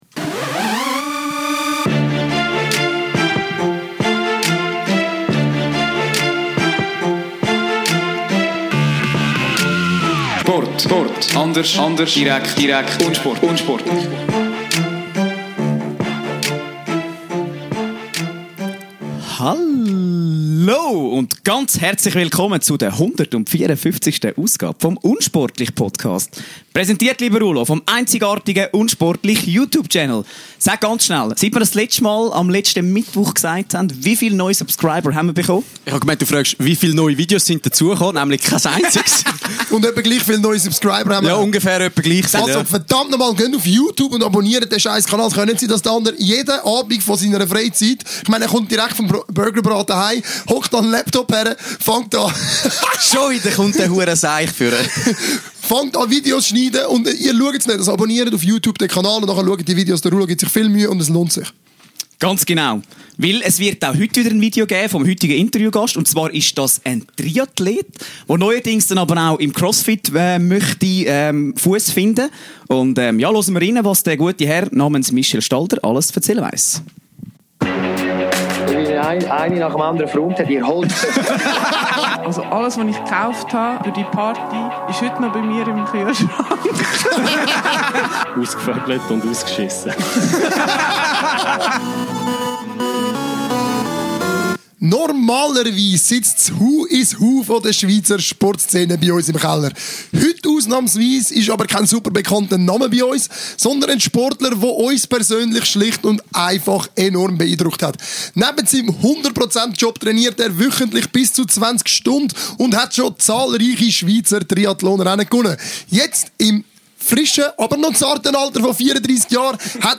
20.11.2019 – Wir sind bekannt dafür, dass wir jede Woche das Who is Who der Schweizer Sportprominenz zu uns in den Keller entführen.